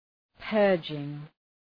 Προφορά
{‘pɜ:rdʒıŋ}
purging.mp3